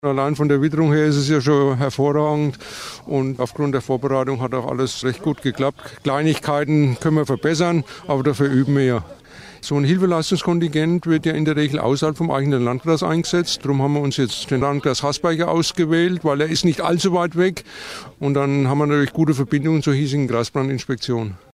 Deshalb fand in Sand am Main am Samstag eine große Übung des sogenannten Hilfeleistungskontigents des Landkreises Bad Kissingen statt. Laut Kreisbrandrat Benno Metz ist die Übung gut gelaufen: